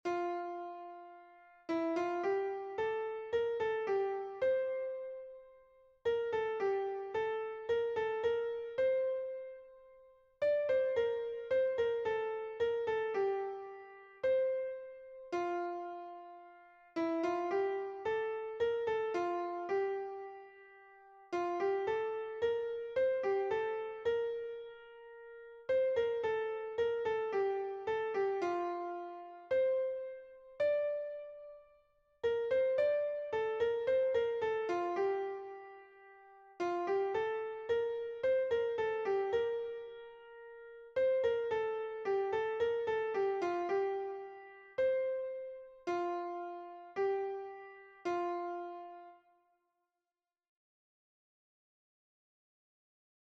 It's a slow, reflective sort of melody in F major.